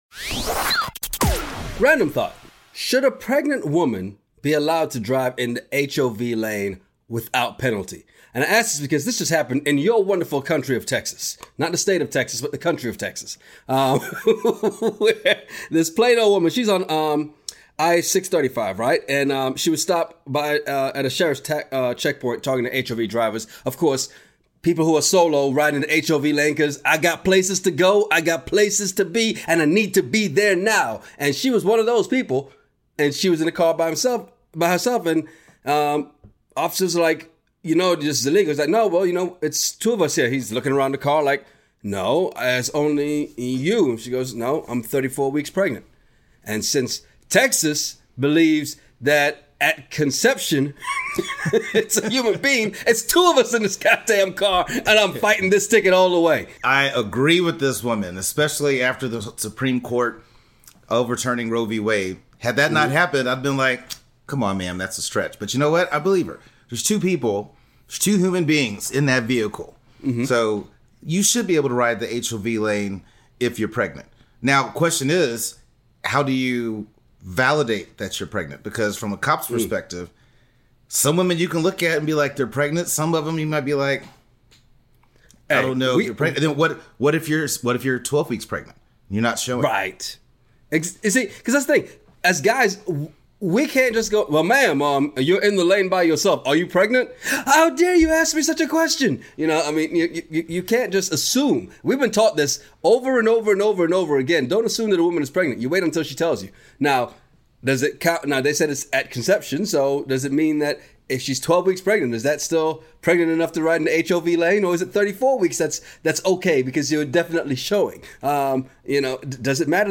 Ever been somewhere and overheard two guys having a crazy conversation over random topics?